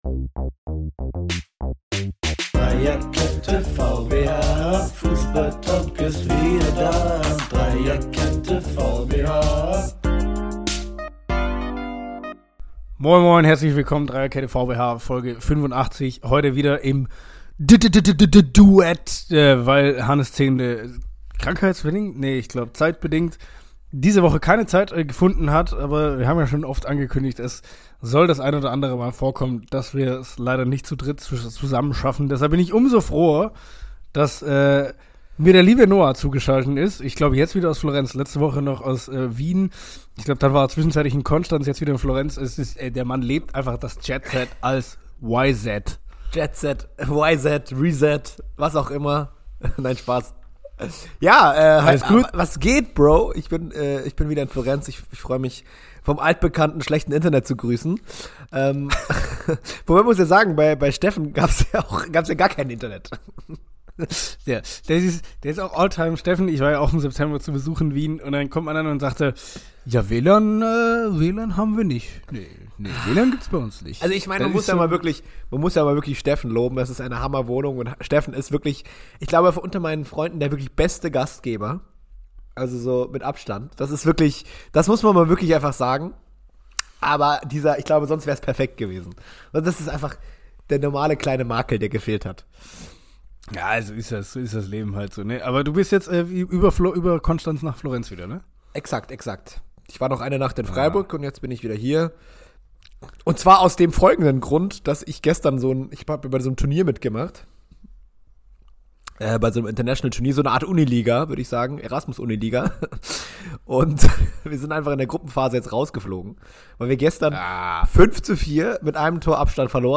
in einem gleichsamen Duett leider nur zu zweit durch die Fußball-Woche. Von Freiburger Pokalpleiten über Verschwörungstheorien bis zur nächsten WM der Schande war alles dabei.